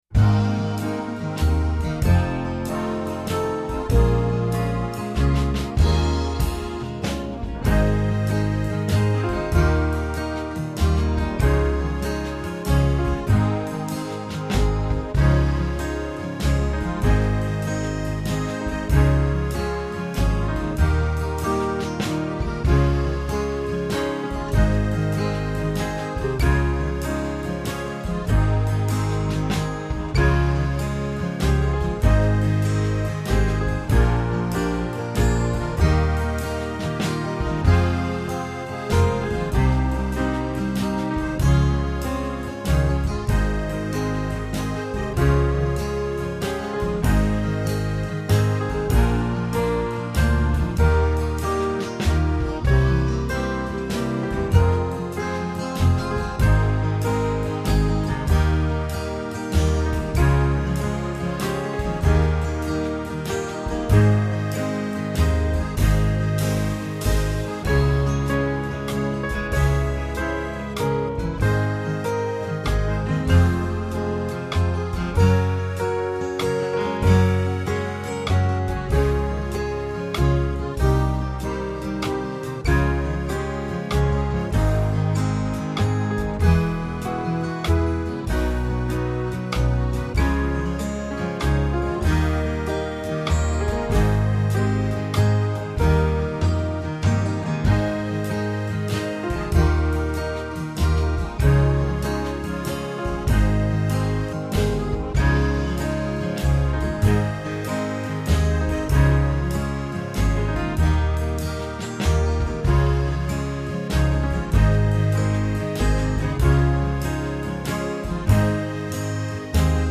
minus one